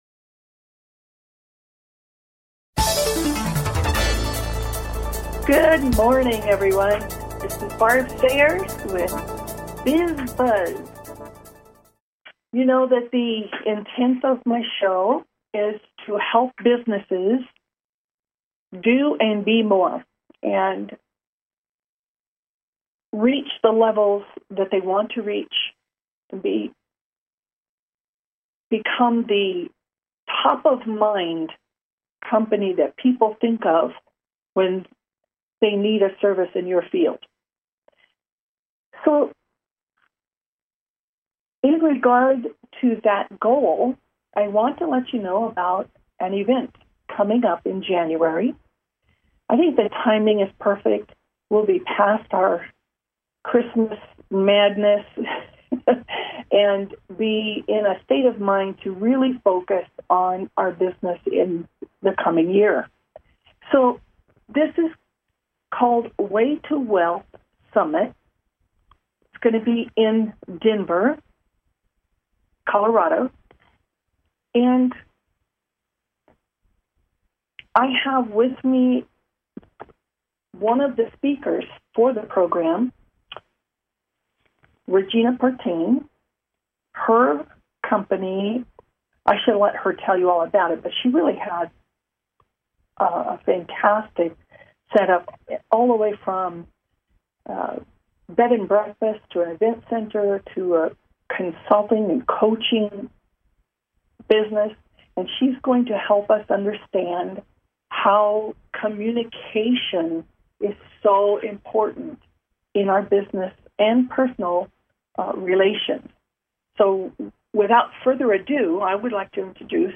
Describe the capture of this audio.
Call-ins encouraged!